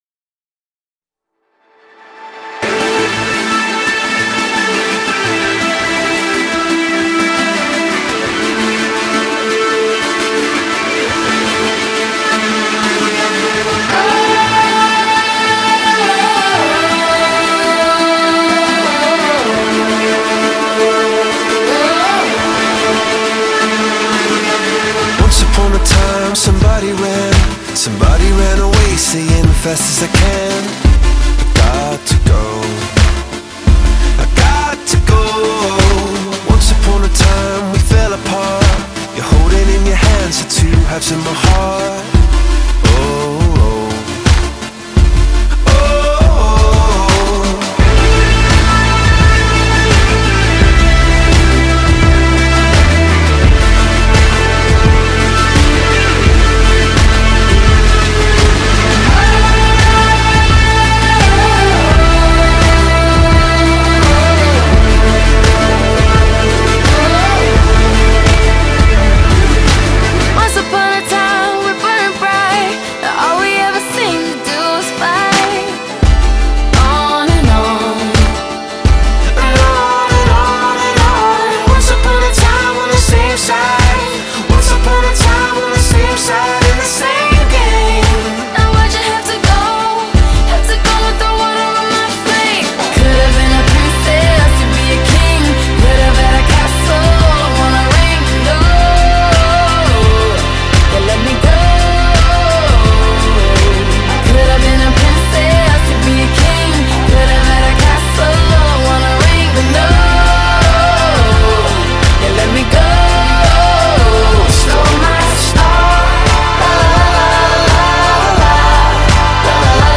R&B, Pop, Electro, Rock